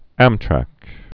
(ămtrăk)